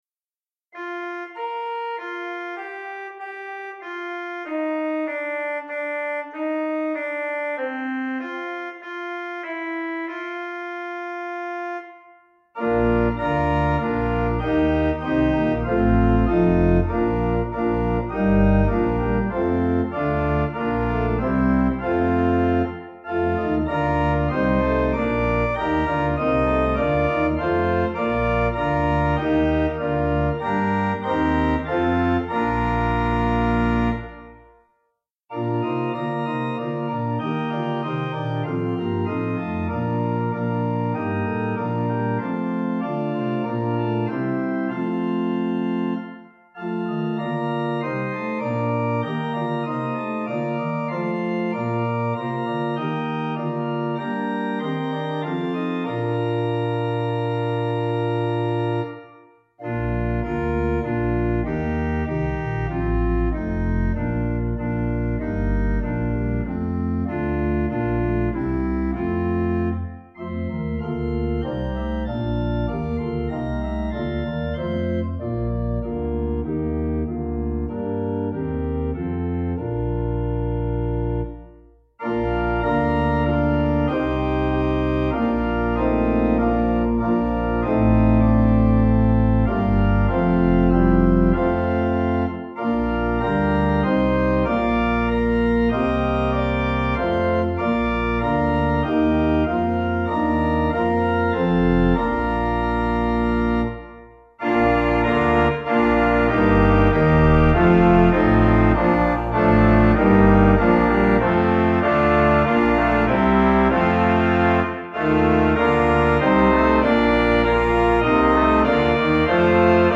Organ: Little Waldingfield